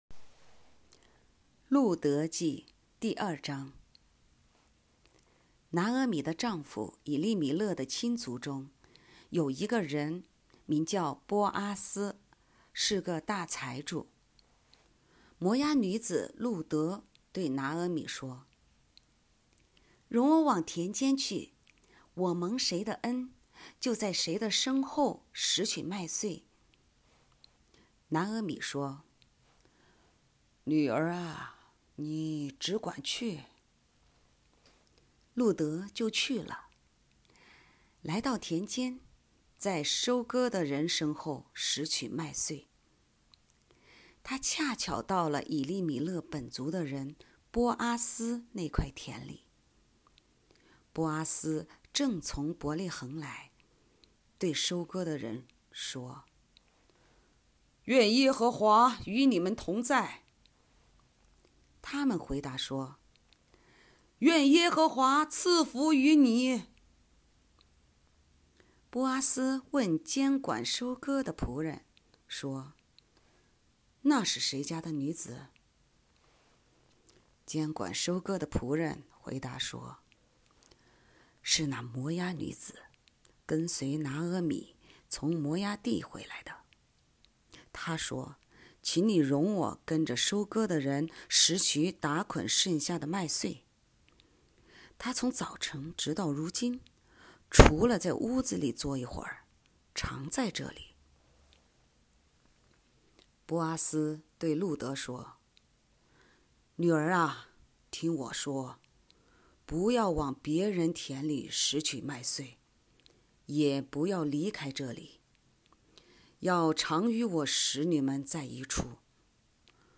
经文朗读：路得记第二章